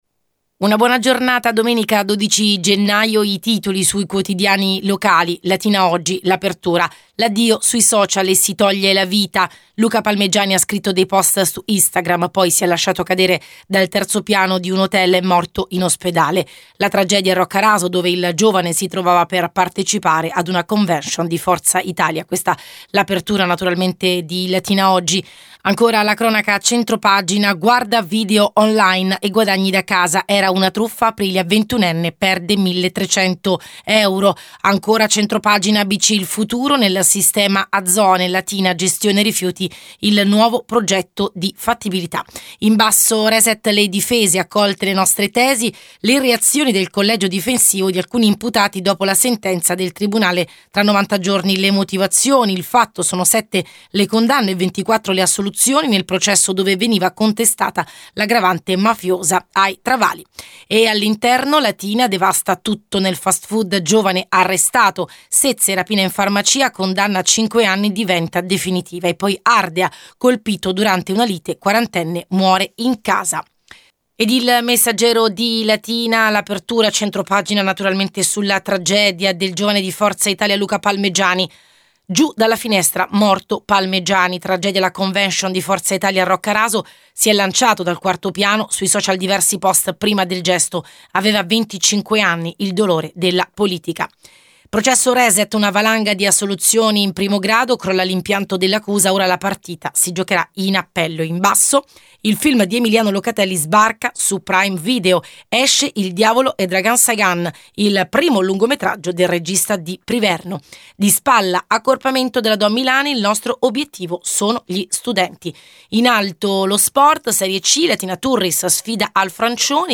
rassegna-stampa-12-geannio.mp3